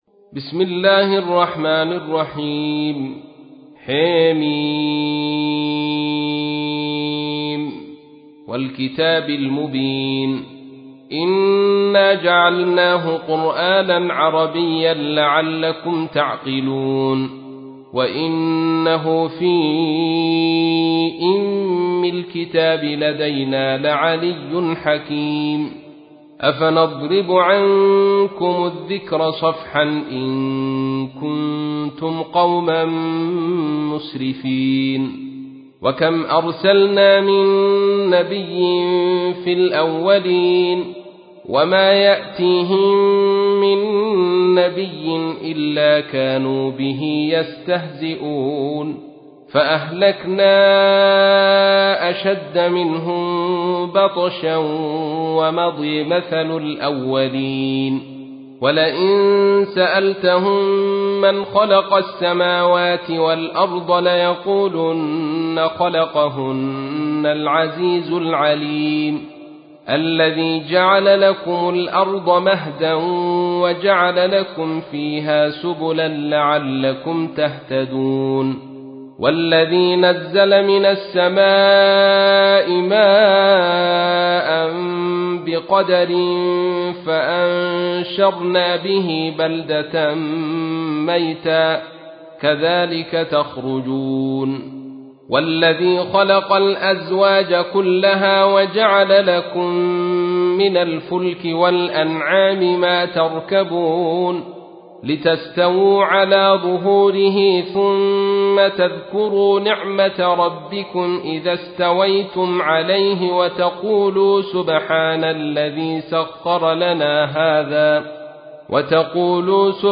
تحميل : 43. سورة الزخرف / القارئ عبد الرشيد صوفي / القرآن الكريم / موقع يا حسين